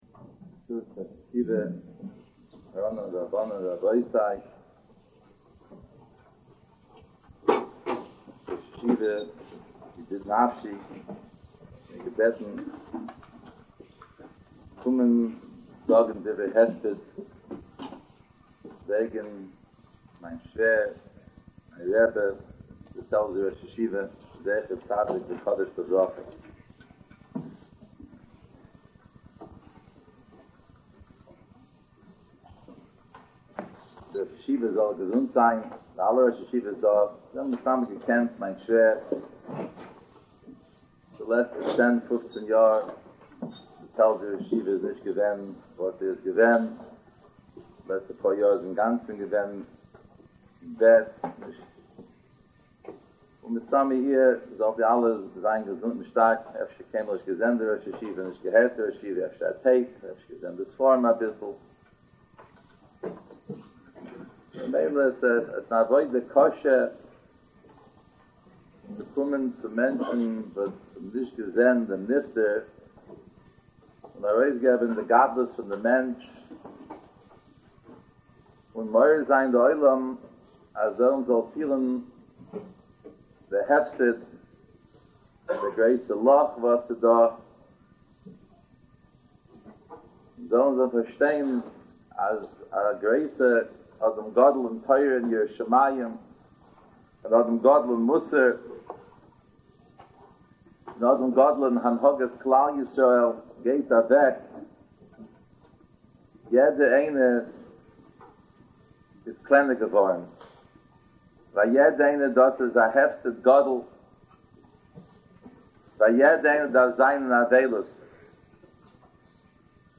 Recent Sermons
Hesped
at the Yeshiva in South Fallsburg New York